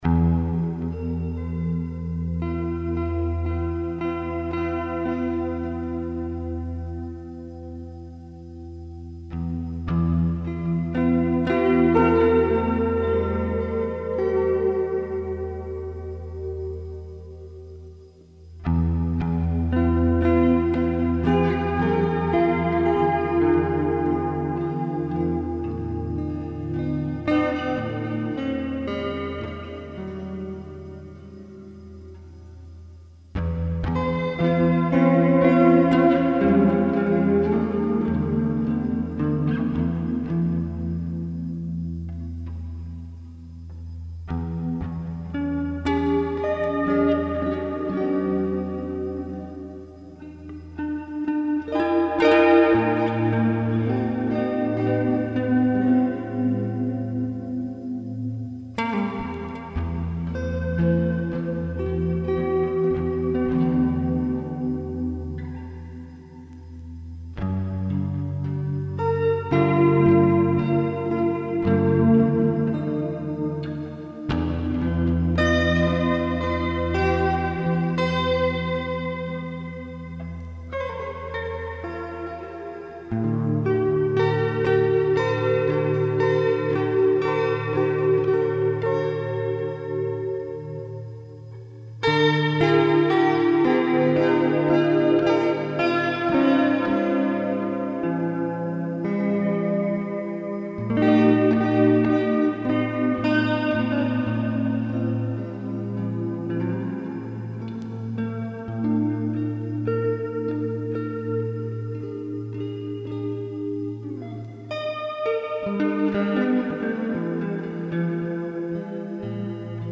Piece in E minor